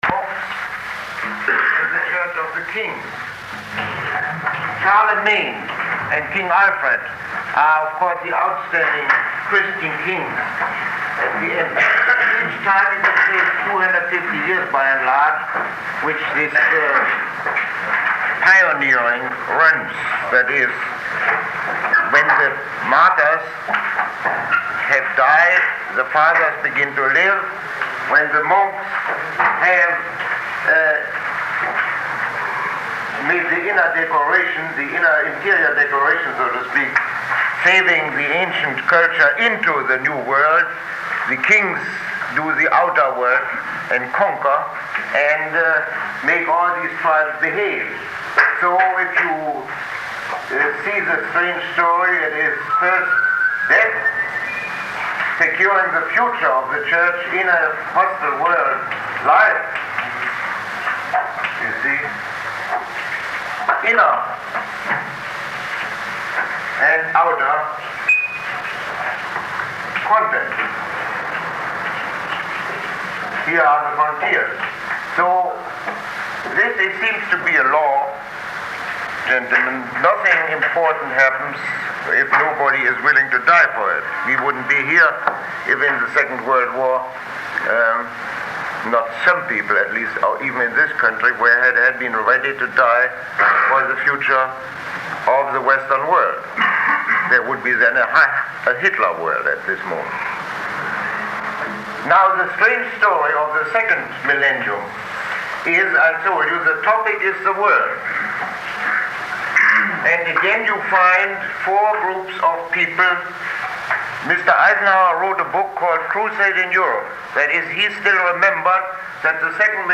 Lecture 09